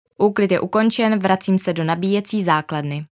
Hovorí po slovensky
Vysávač informuje o svojom chode správami v slovenskom jazyku.